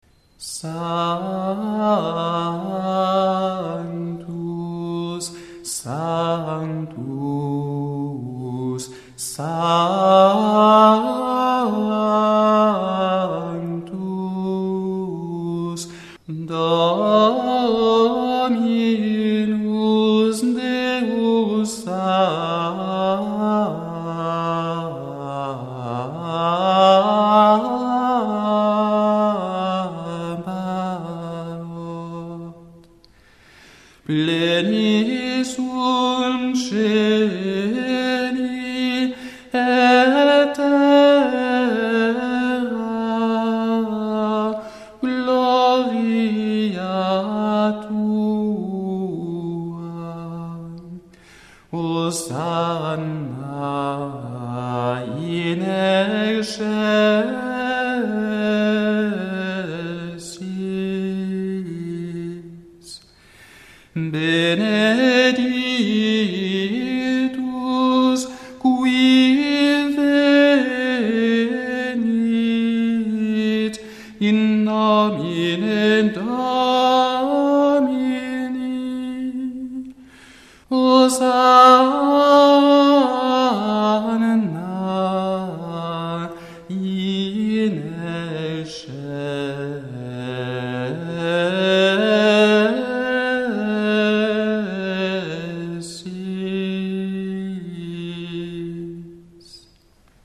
Nous sommes en présence d’un 6e mode, mode de Fa lumineux et joyeux, bien campé sur ses trois cordes principales, le Fa, le La et le Do, qui sont aisément repérables par leur importance tout au long de la mélodie.
Notons enfin que ce Sanctus descend dans sa quarte grave Fa-Do, en général avant une remontée qui va culminer sur le Do aigu, la mélodie parcourant ainsi tout l’octave, entre le Do grave et le Do aigu.
La finale de cette phrase, in nómine Dómini, est très belle : elle reprend elle aussi l’arpège Fa-La-Do, mais s’achève sur une tenue sur le Do très expressive et bien épanouie.
Ce beau Sanctus, expressif, enthousiaste, chaleureux, mais aussi adouci notamment par la présence des nombreux Sib, doit être chanté de manière fluide, coulante, en suivant bien les mouvements de la ligne mélodique.